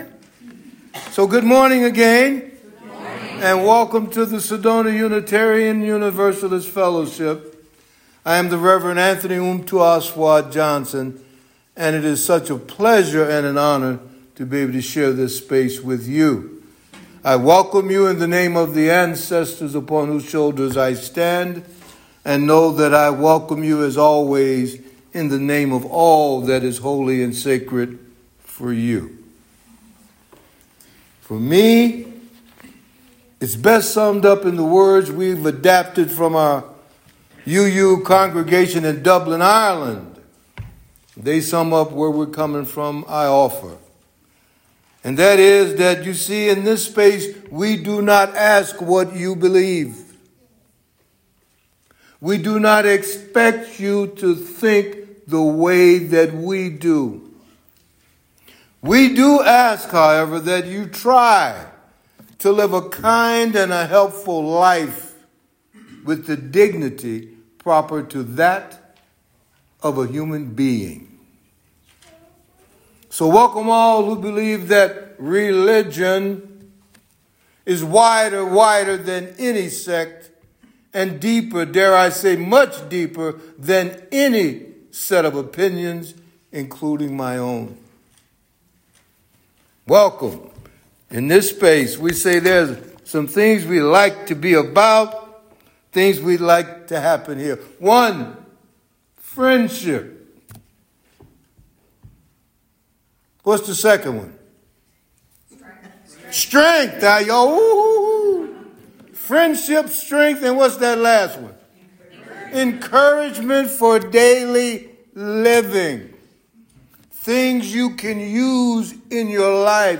Click below to listen to the recorded sermon for today’s service. https